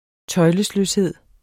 Udtale [ ˈtʌjləsløsˌheðˀ ]